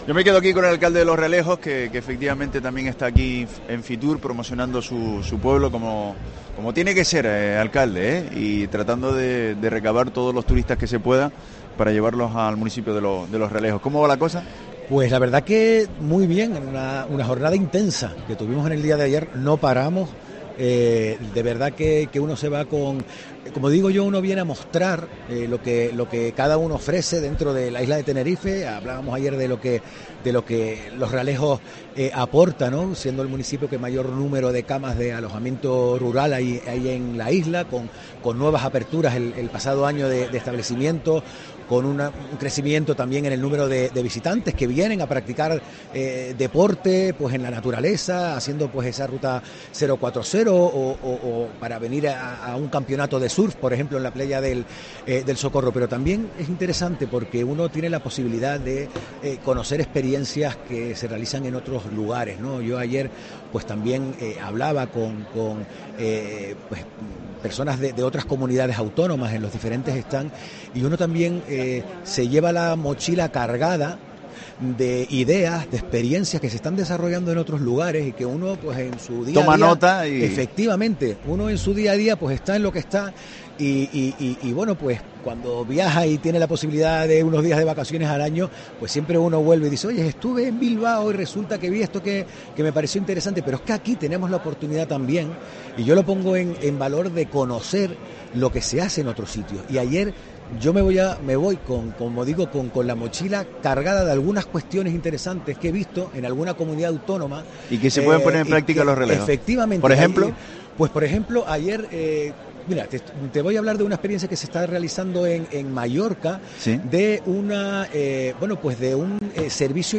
Adolfo González, alcalde de Los Realejos, en FITUR 2023